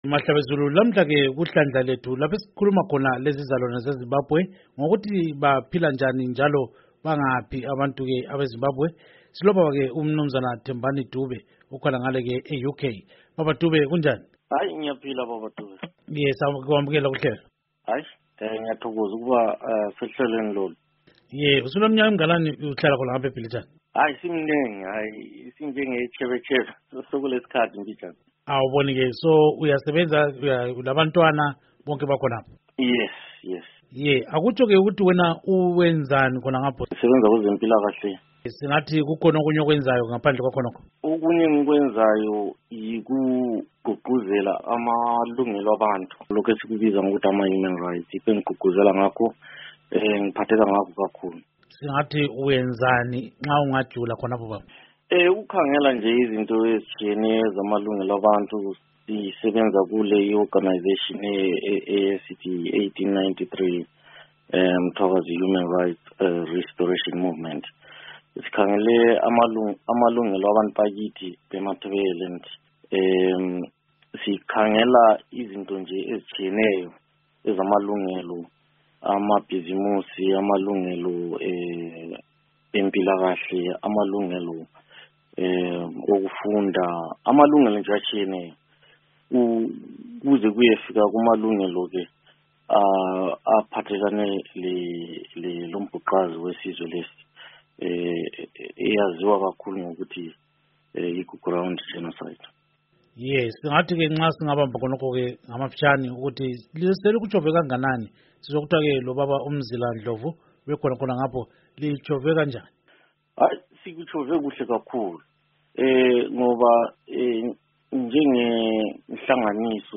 Akesizwe ingxoxo